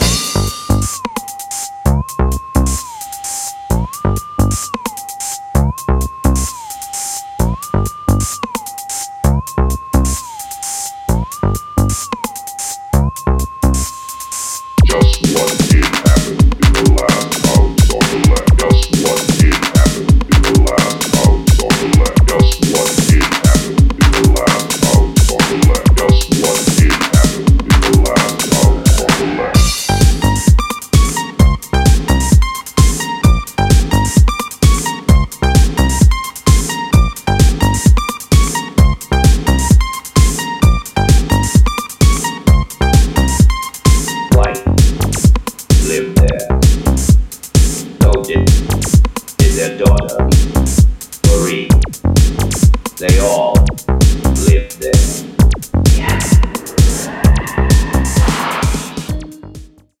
far out and retro-futuristic freakdance 12”